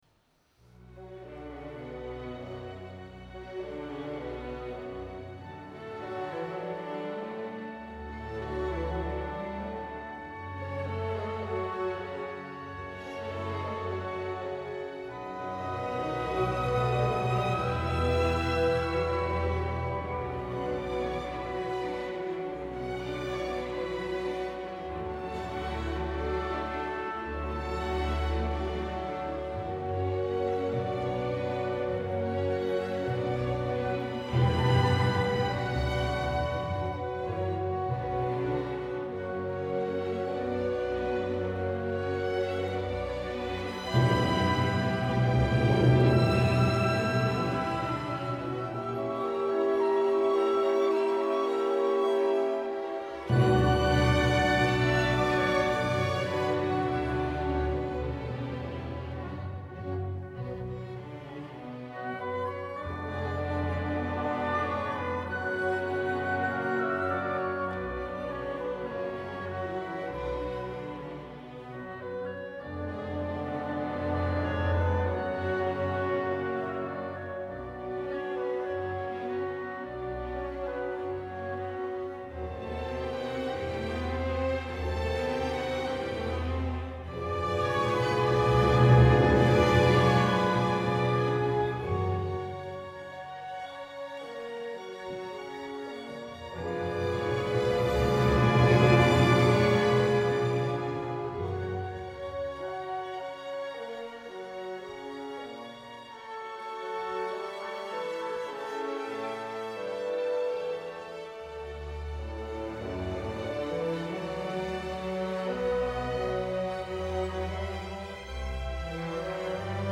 Μουσικά Σύνολα ΕΡΤ – Εθνική Συμφωνική Ορχήστρα
Ωδείο Αθηνών – 17 Ιανουαρίου 2024